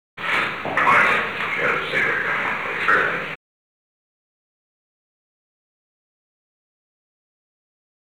Secret White House Tapes
Conversation No. 947-2
Location: Oval Office
The President met with an unknown man.